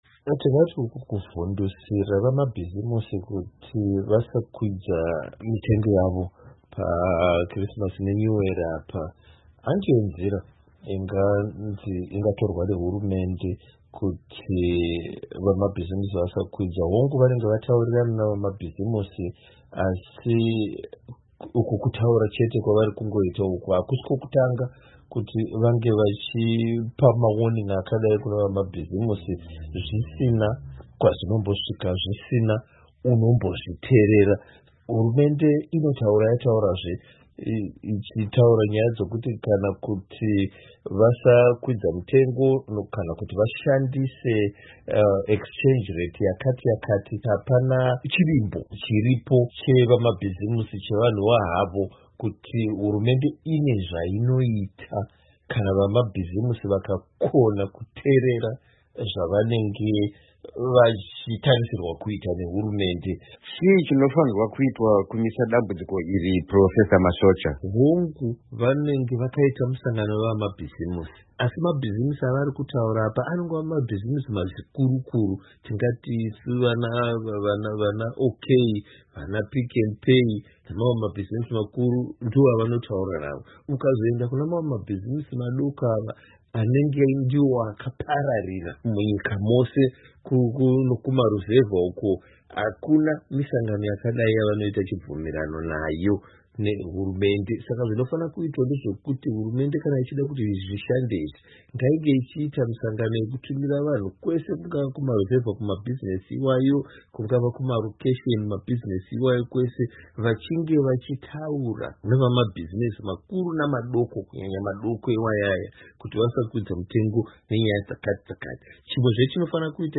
Hurukuron